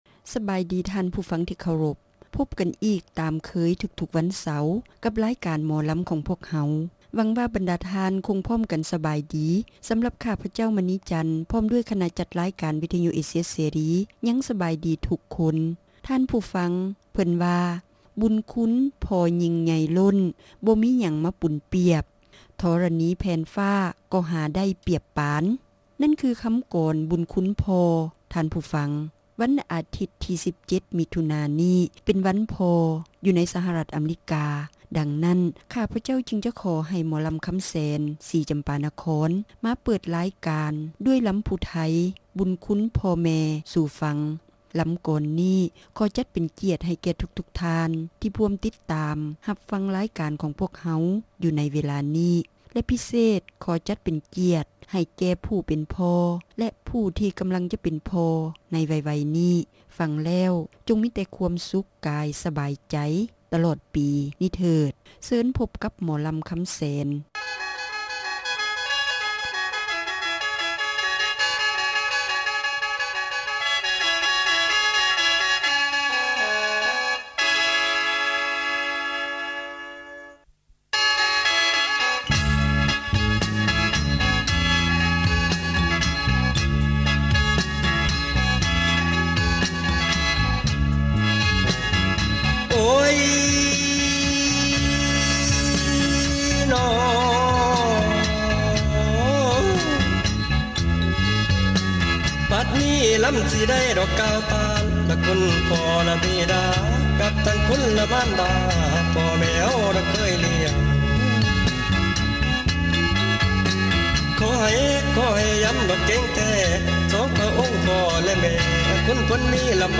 ຣາຍການໜໍລຳ ປະຈຳສັປະດາ ວັນທີ 15 ເດືອນ ມິຖຸນາ ປີ 2007